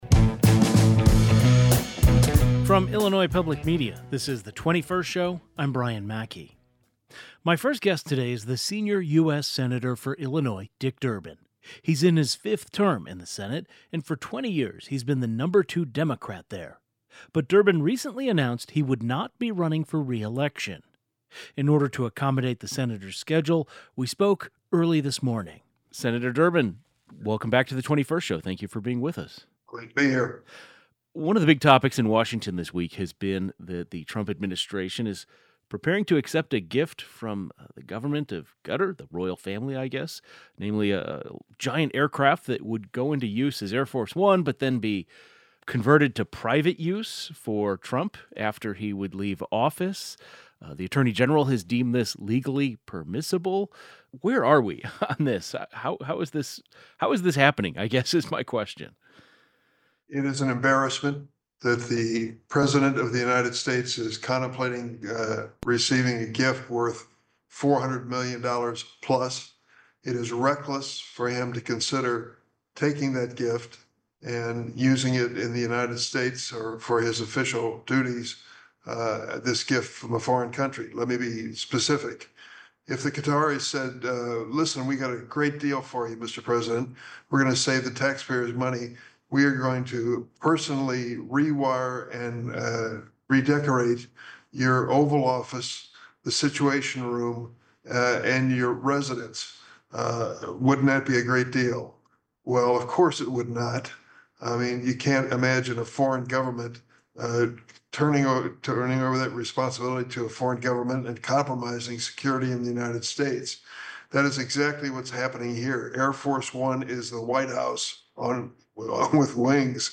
One-on-one with Senator Dick Durbin ahead of retirement
GUEST Sen. Dick Durbin Democrat of Illinois Tags